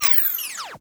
TAPE STOP_15.wav